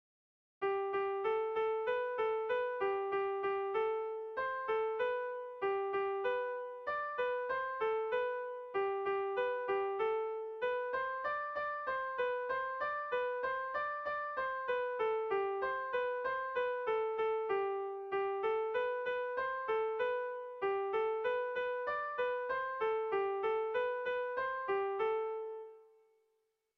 Erlijiozkoa
Hamarreko txikia (hg) / Bost puntuko txikia (ip)
ABDEB2